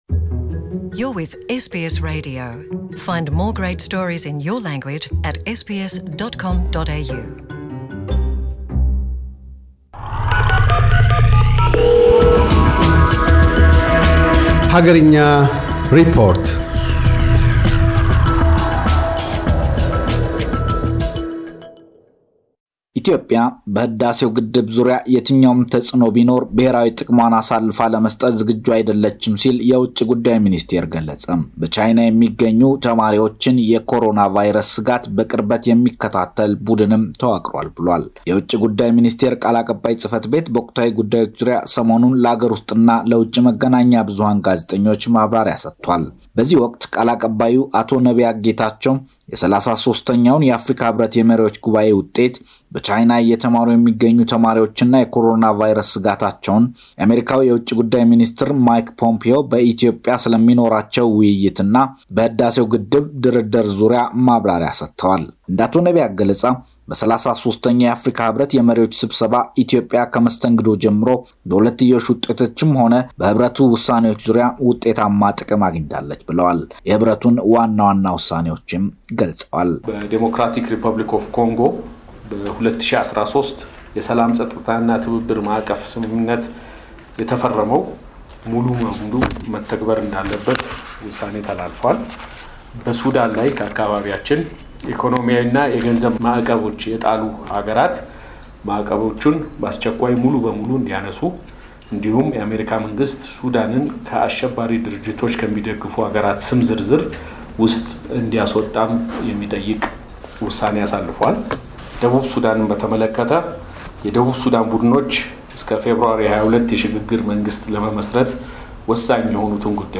አገርኛ ሪፖርት - የኢትዮጵያ ውጭ ጉዳይ ሚኒስቴር ቃል አቀባይ አቶ ነቢያት ጌታቸው፤ በተለያዩ ወቅታዊ ጉዳዮች ዙሪያ የሰጡትን ጋዜጣዊ መግለጫ አጠናቅሯል።